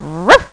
00138_Sound_Hund.mp3